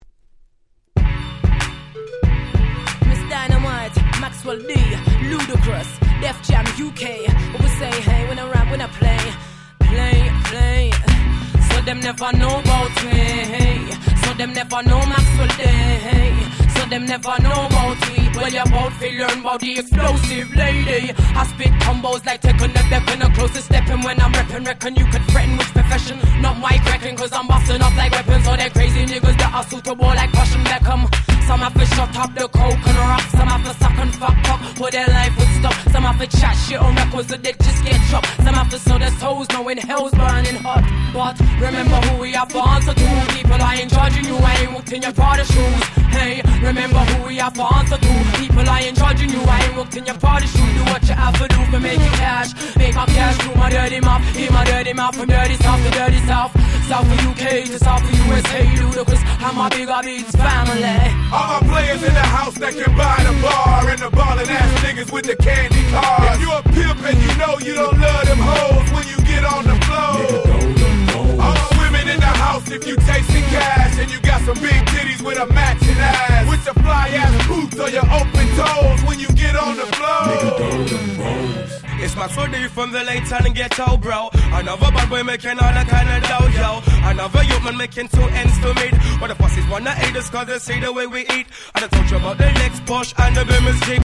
01' Super Hit Hip Hop !!